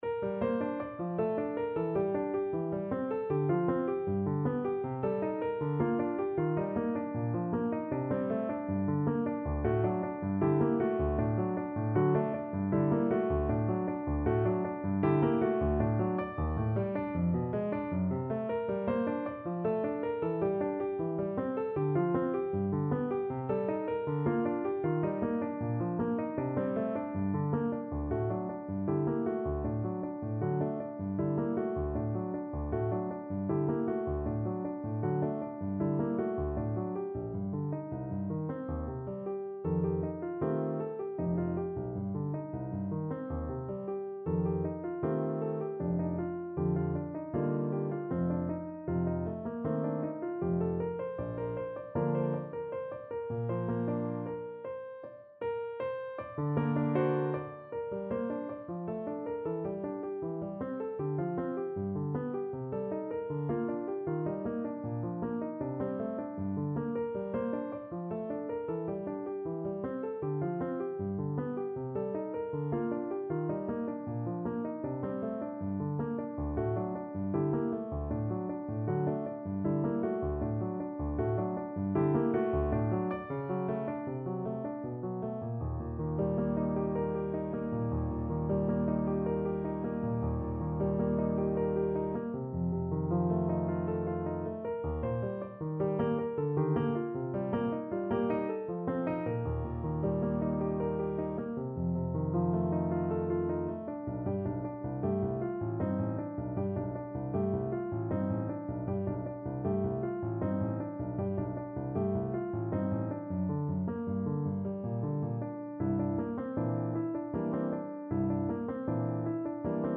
3/4 (View more 3/4 Music)
Classical (View more Classical Trumpet Music)